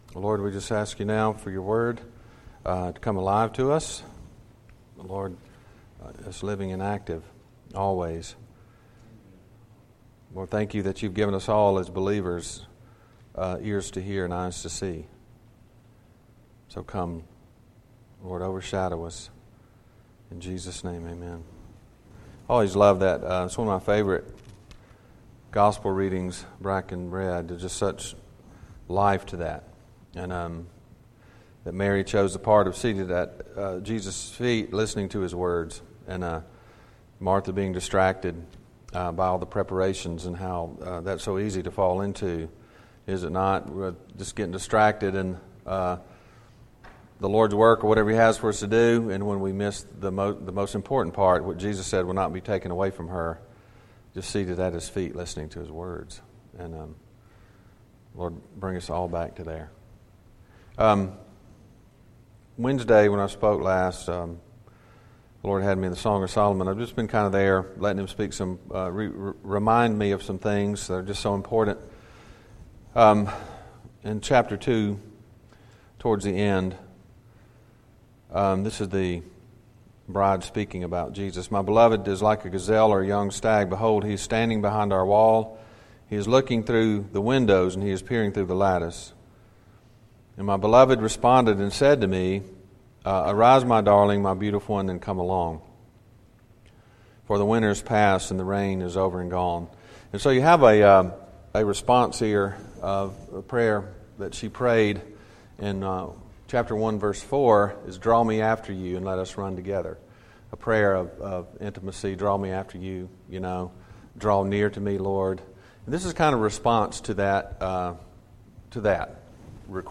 Series: Audio Devotionals